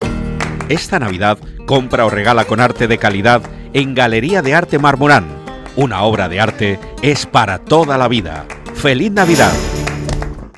ANUNCIO ONDA CERO